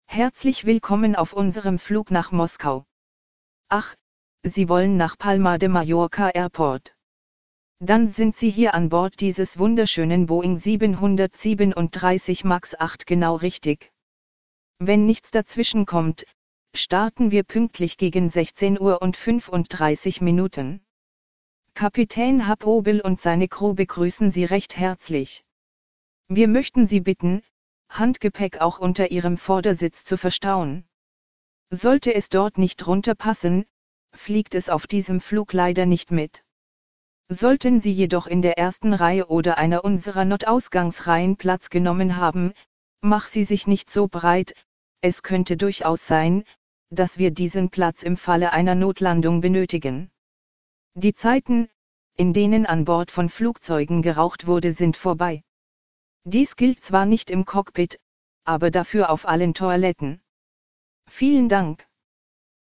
BoardingWelcome.ogg